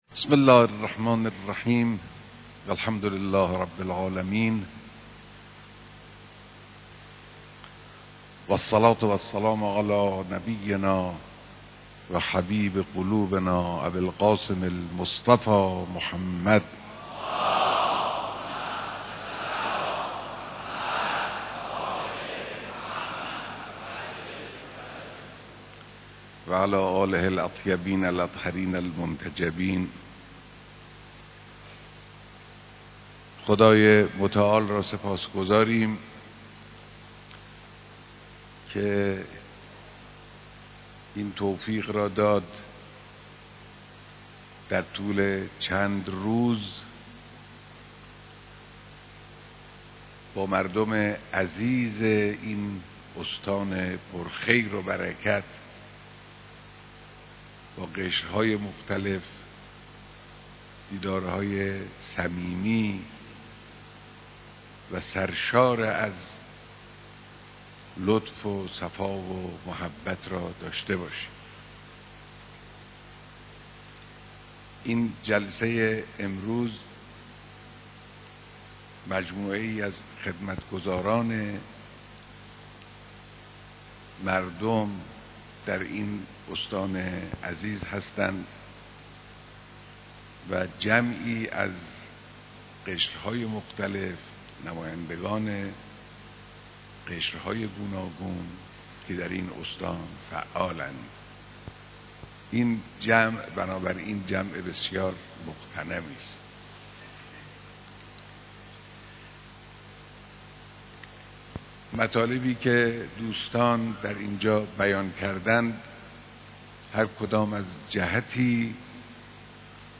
بیانات در دیدار جمعی از نخبگان و برگزیدگان اقشار مختلف استان خراسان شمالی